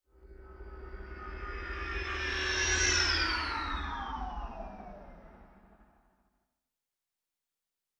Distant Ship Pass By 2_2.wav